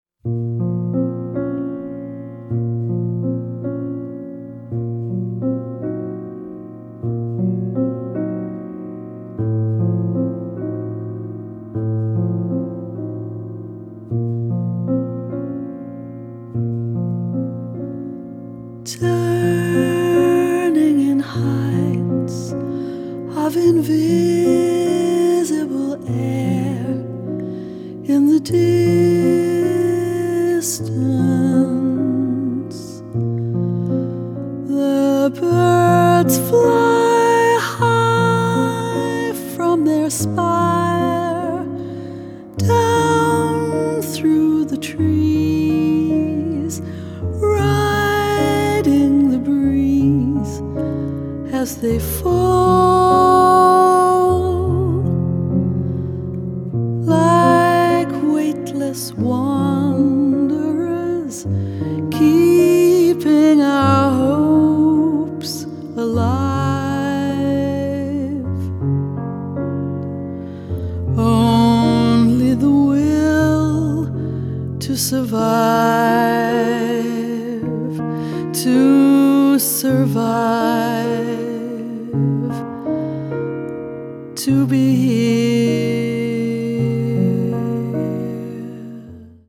piano
contemporary jazz   deep jazz   jazz vocal